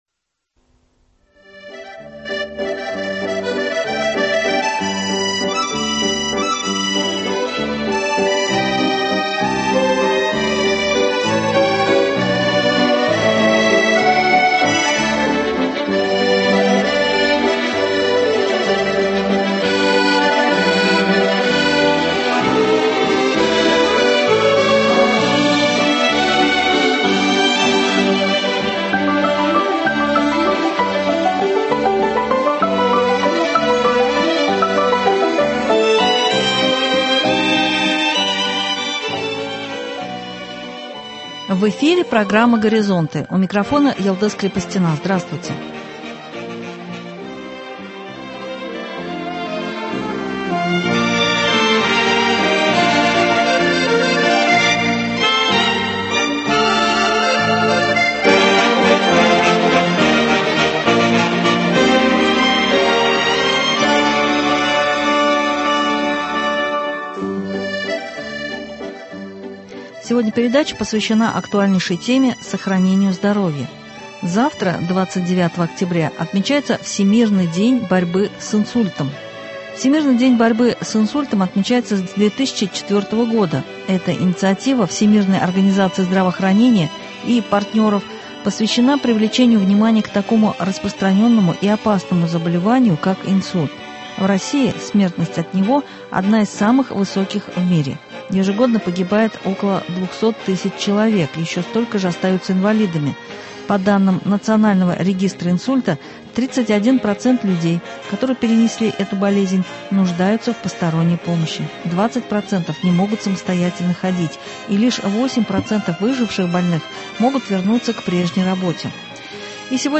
И сегодня в нашем эфире прозвучит интервью